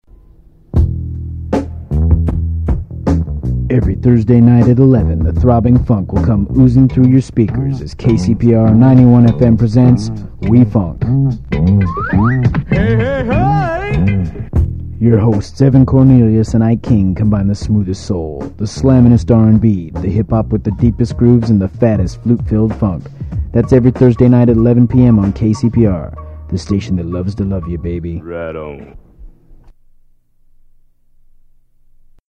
Form of original Audiocassette